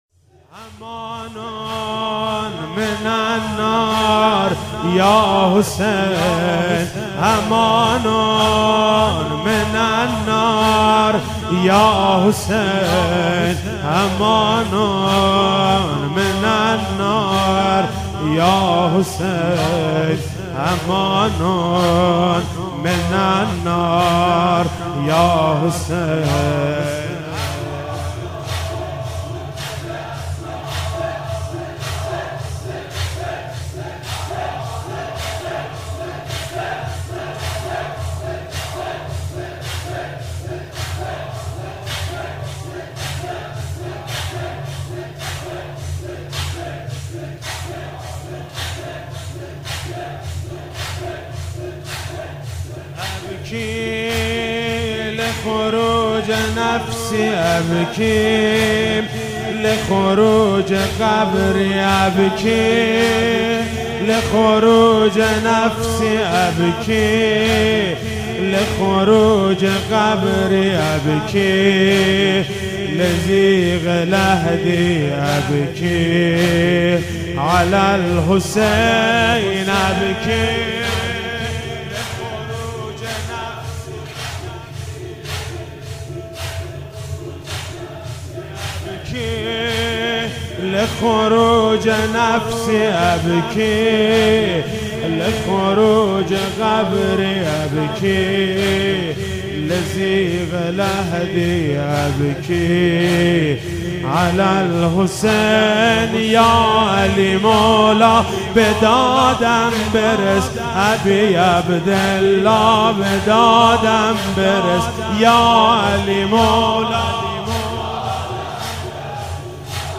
شب 22 ماه مبارک رمضان 96(قدر) - زمینه - امان و من النار یاحسین